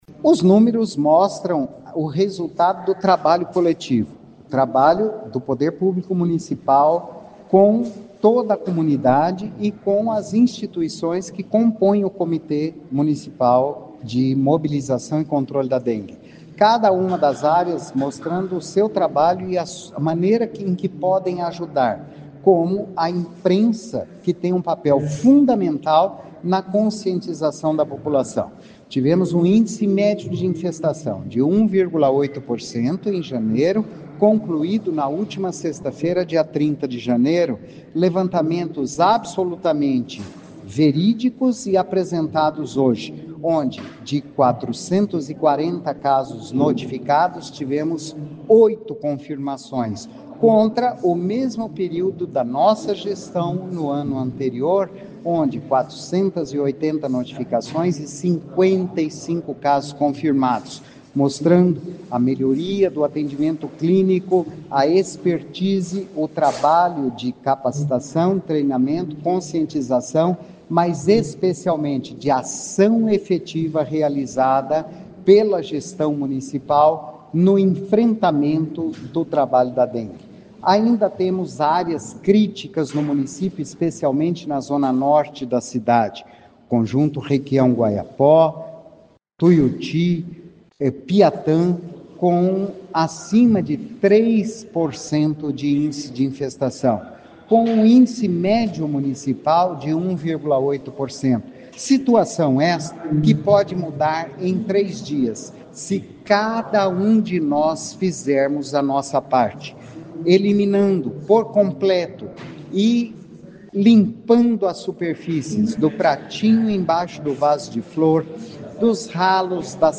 Ouça o que diz o secretário de saúde Antônio Carlos Nardi: